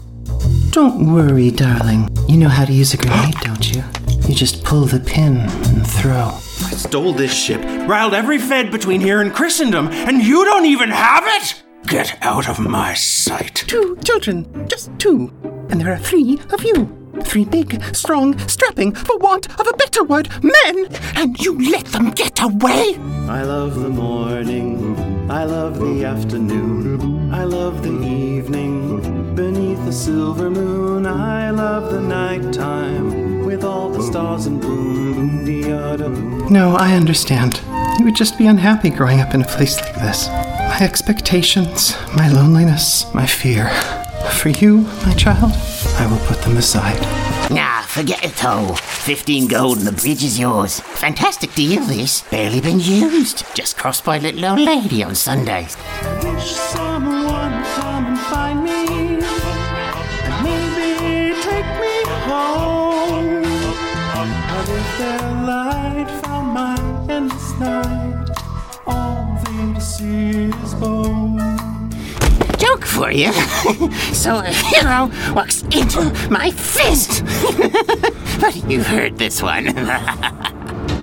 Animation VO Demo –